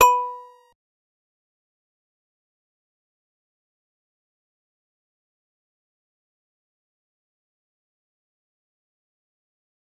G_Musicbox-B4-pp.wav